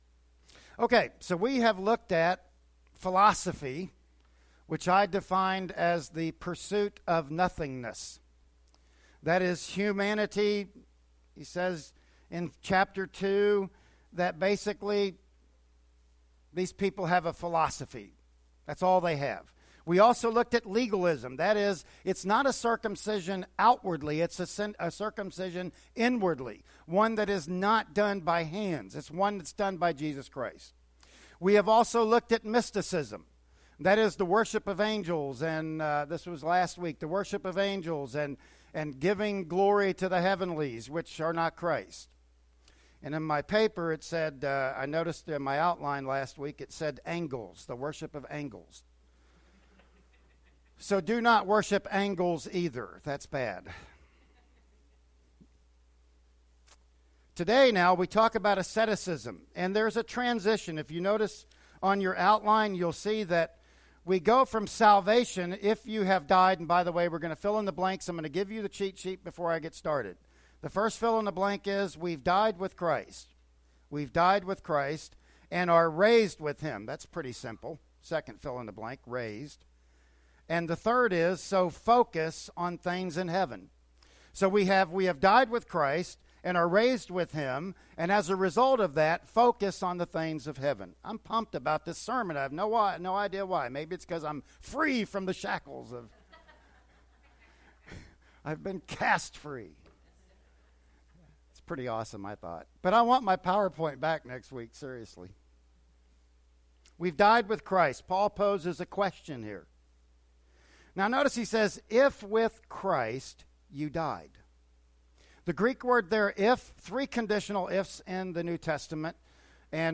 "Colossians 2:20-3:4" Service Type: Sunday Morning Worship Service Bible Text